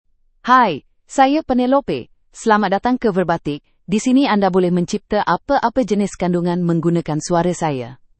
FemaleMalayalam (India)
PenelopeFemale Malayalam AI voice
Voice sample
Listen to Penelope's female Malayalam voice.
Penelope delivers clear pronunciation with authentic India Malayalam intonation, making your content sound professionally produced.